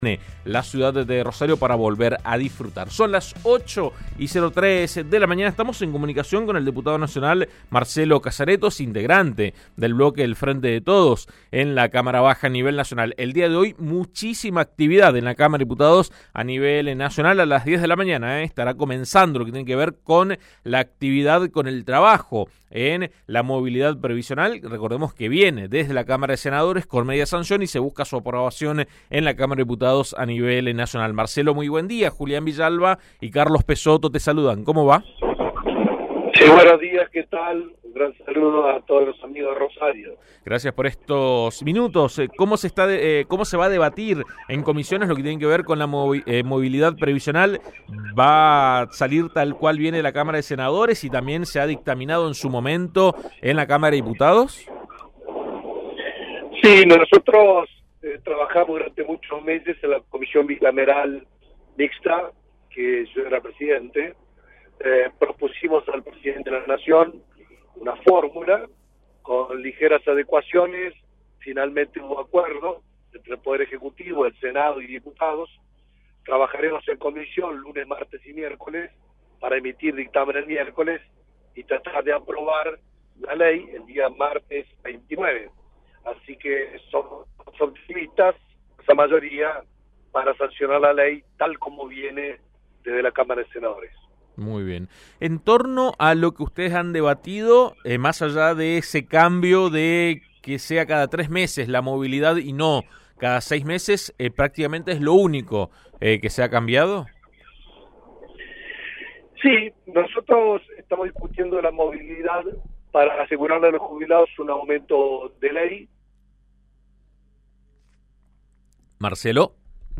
La cámara de diputados comienza a tratar hoy el proyecto de movilidad previsional que ya cuenta con media sanción del Senado y que reinstala la fórmula que estuvo vigente durante el gobierno de Cristina Fernández. AM 1330 habló al respecto con el diputado nacional y titular de la Comisión de Previsión Social, Marcelo Casaretto